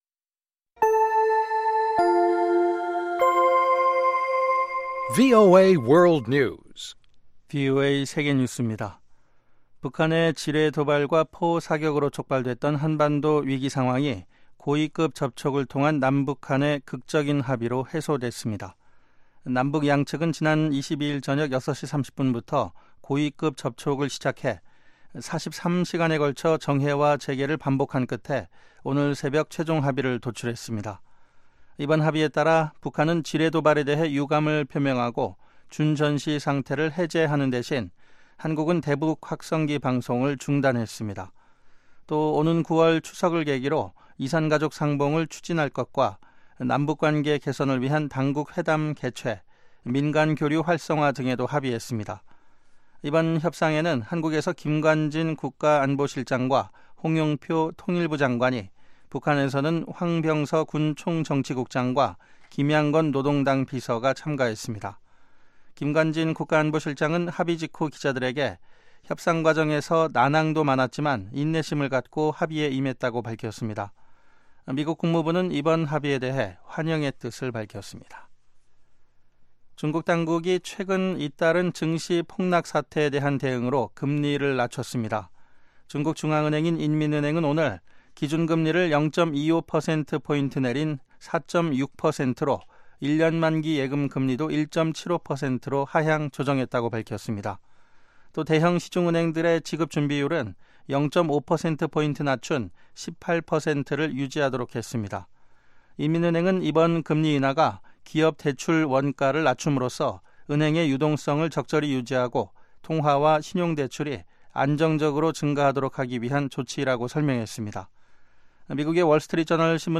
VOA 한국어 방송의 간판 뉴스 프로그램 '뉴스 투데이' 3부입니다. 한반도 시간 매일 오후 11시부터 자정까지 방송됩니다.